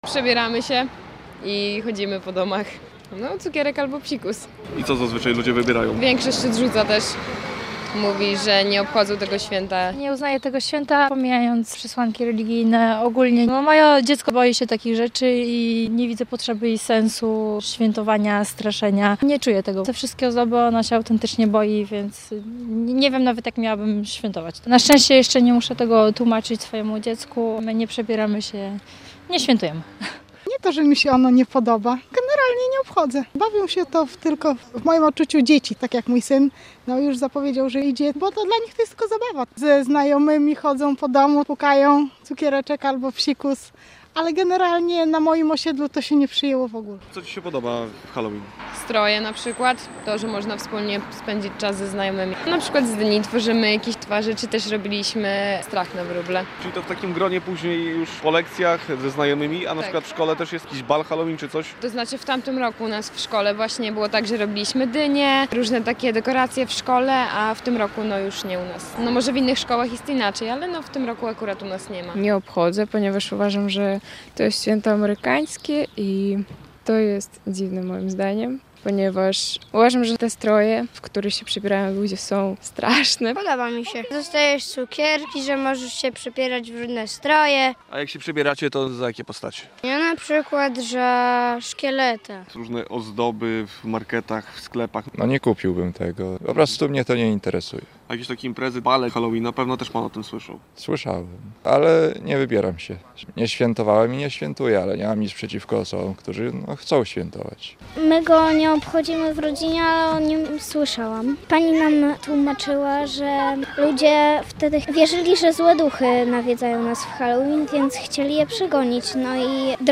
[sonda]